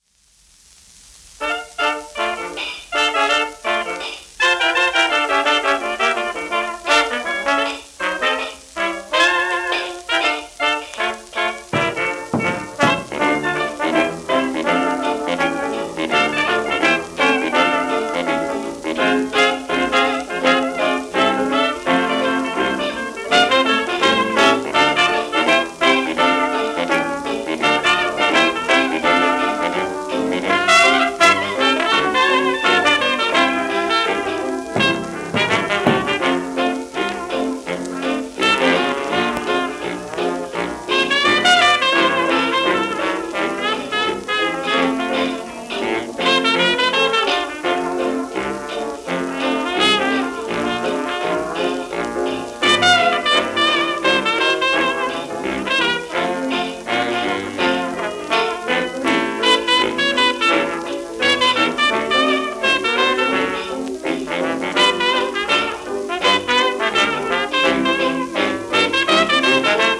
ジャズの歴史的録音のスプリット盤。